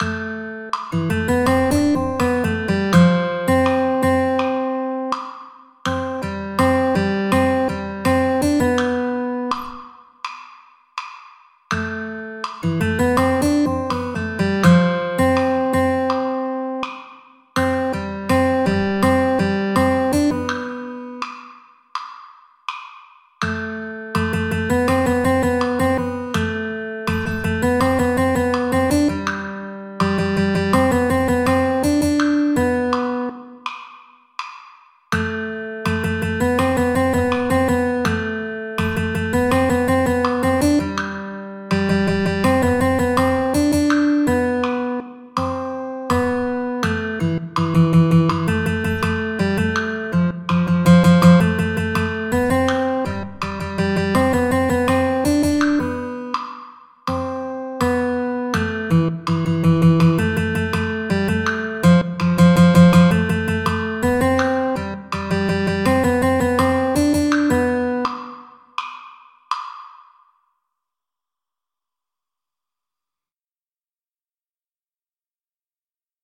para flauta, y xilófonos.
(velocidad rápida)